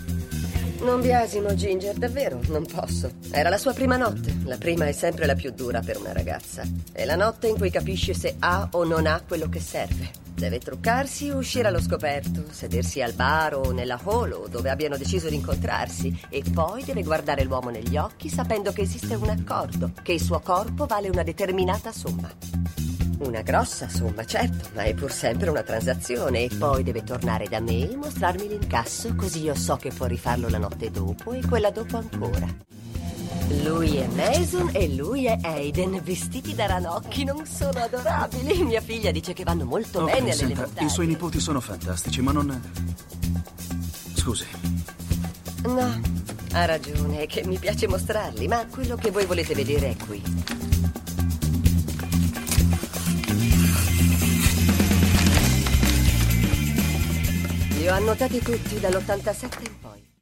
nel telefilm "Scandal", in cui doppia Mimi Kennedy.